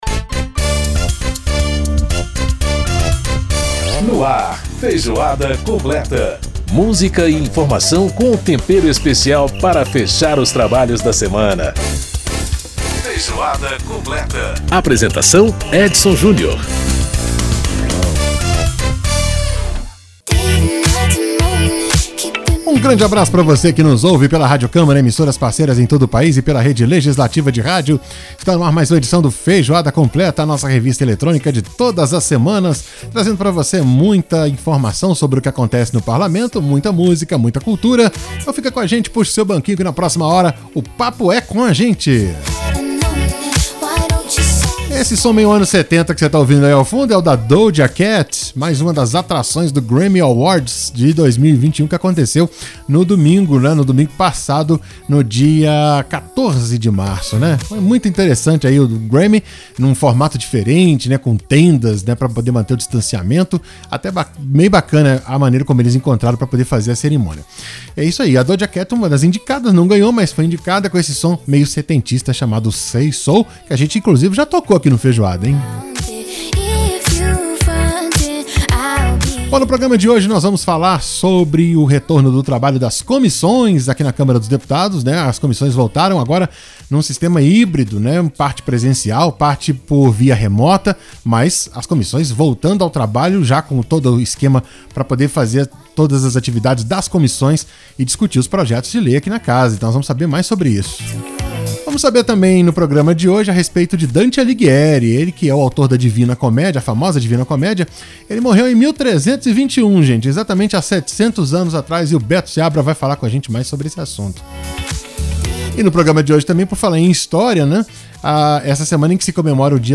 Para entender mais sobre o trabalho das comissões, conversamos com o presidente da comissão de Legislação Participativa, deputado Waldenor Pereira (PT-BA).